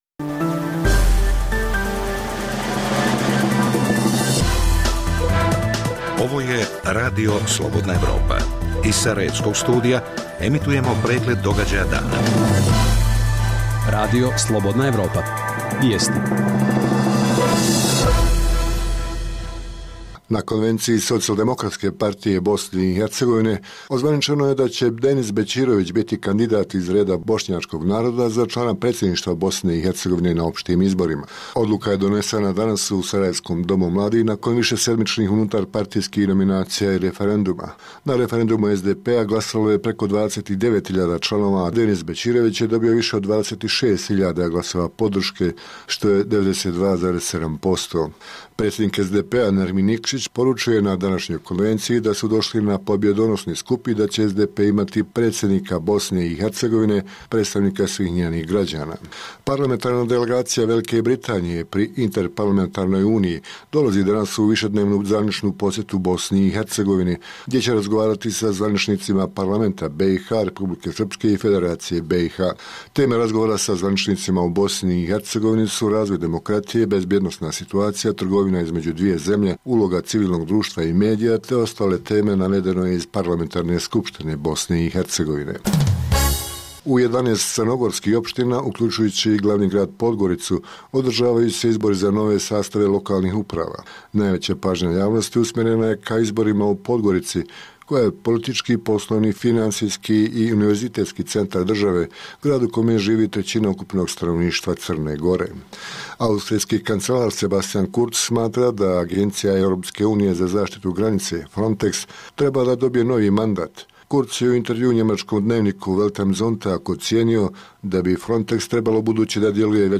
Četvrta godišnja konferencija o stanju ljudskih prava i sloboda u BiH, održana u Bihaću, u focus stavila prava migranata. Intervju